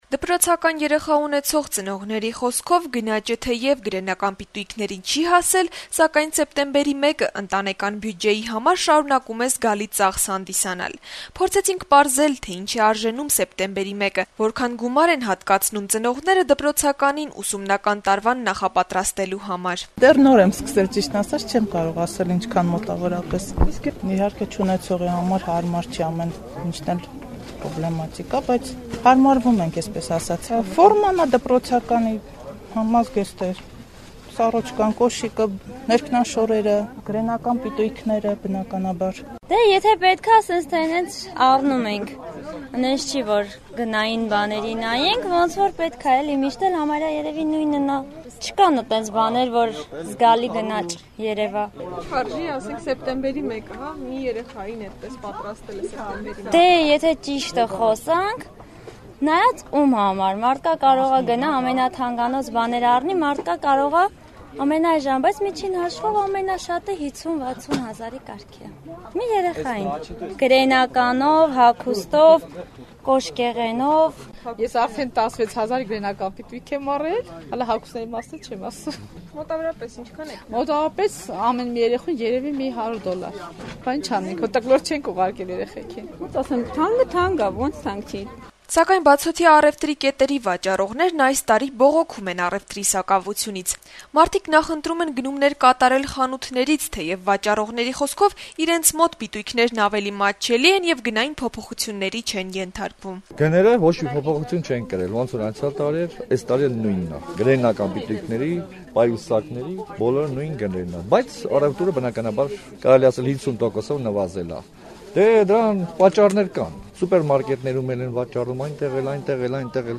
«Ազատություն» ռադիոկայանը զրուցել է երեխաներին ուսումնական նոր տարուն նախապատրաստող ծնողների հետ` հետաքրքրվելով, թե որքան գումար է անհրաժեշտ դրա համար: